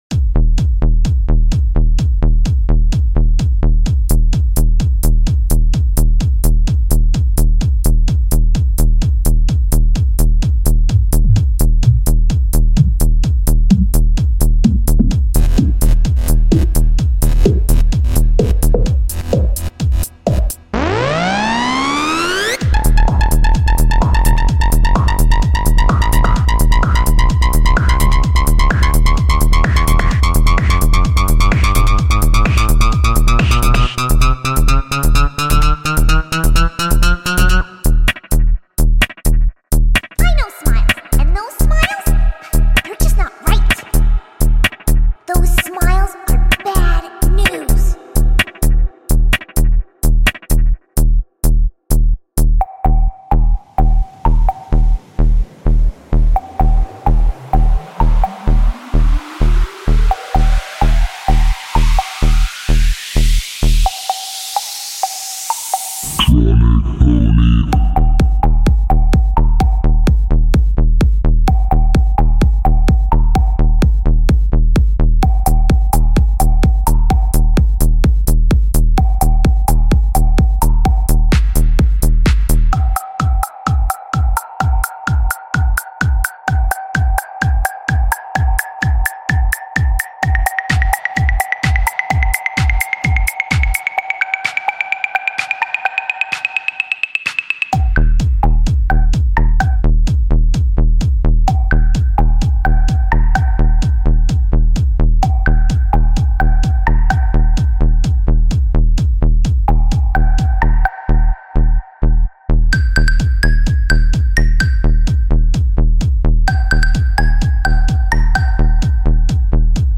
Something realy dark :3
dark minimal